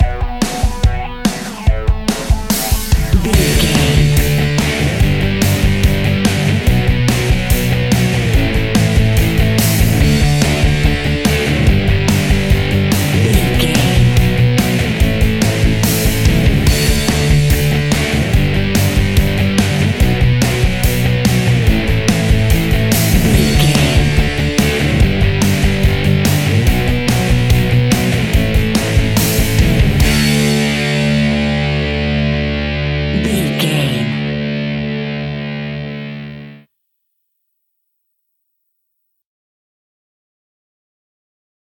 Epic / Action
Fast paced
Ionian/Major
D
hard rock
distortion
punk metal
instrumentals
Rock Bass
Rock Drums
heavy drums
distorted guitars
hammond organ